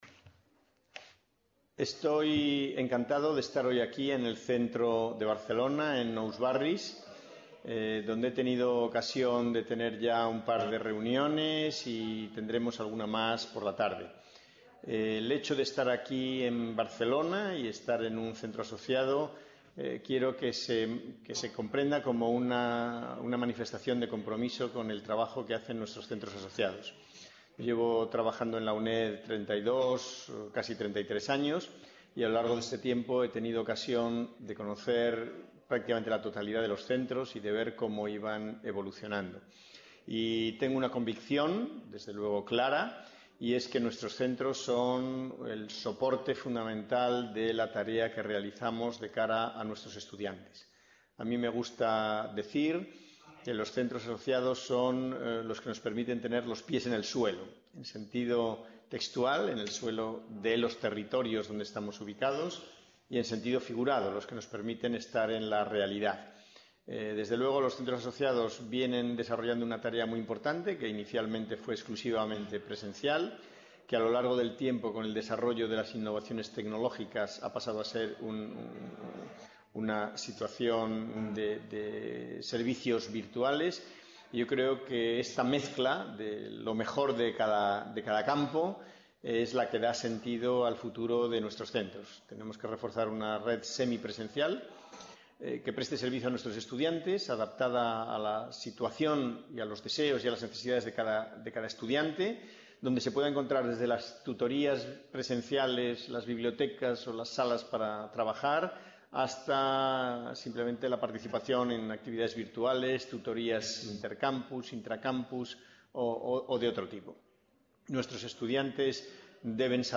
Se emite desde el centro de Nou Barris (Barcelona)